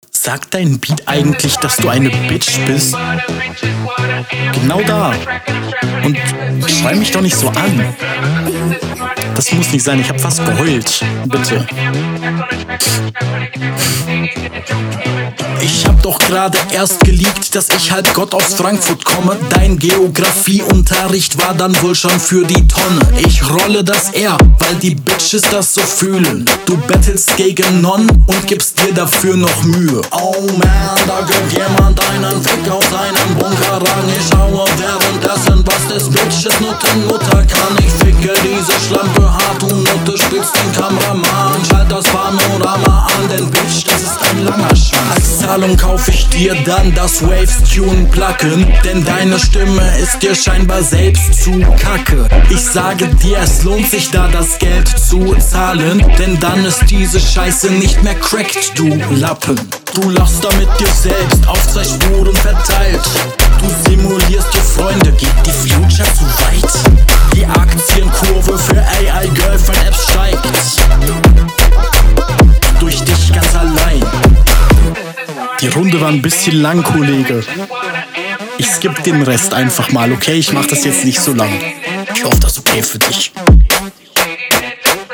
Flow quasi nicht vorhanden.
Rundum sehr monoton.